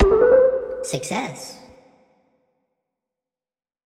Success_V2.wav